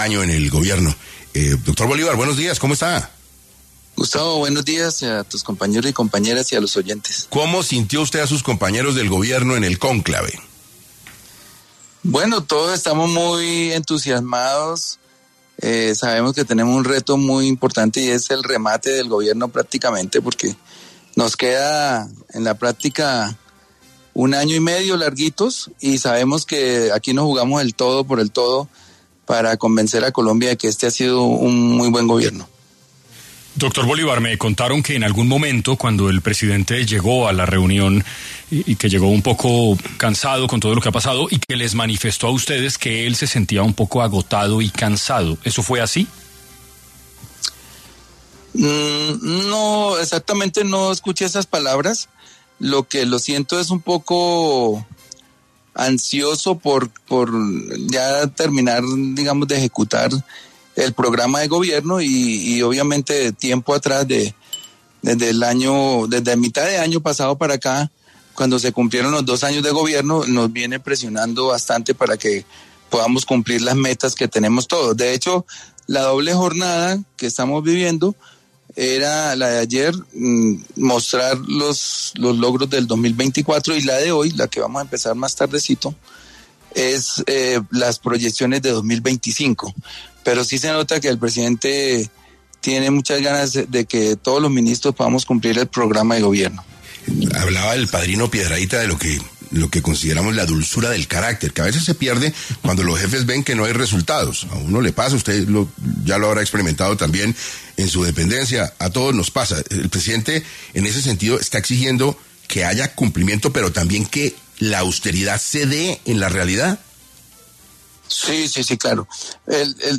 En diálogo con 6AM de Caracol Radio, Gustavo Bolívar, director del Departamento de Prosperidad Social (DPS), aseguró que la principal preocupación del ‘Gobierno del Cambio’ es no tener los recursos suficientes para ejecutar su plan “Colombia, potencia mundial de la vida”.